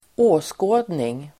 Ladda ner uttalet
Uttal: [²'å:skå:dning]